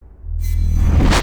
engine_warp_004.wav